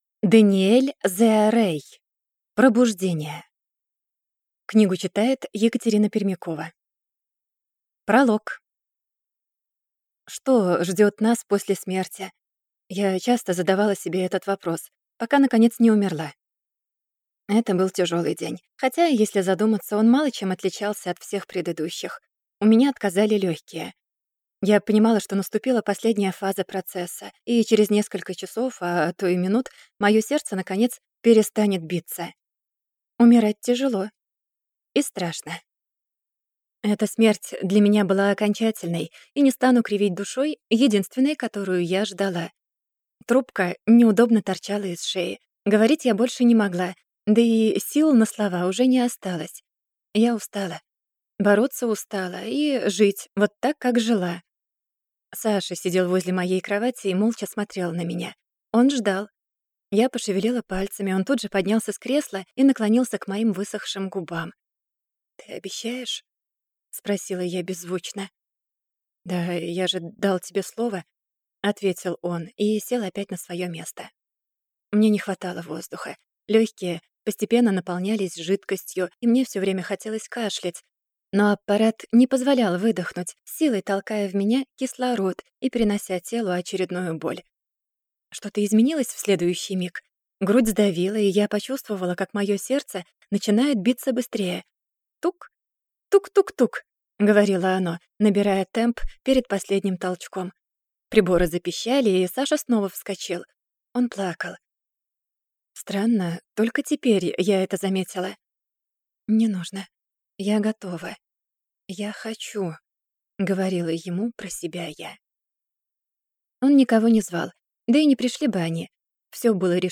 Аудиокнига Пробуждение | Библиотека аудиокниг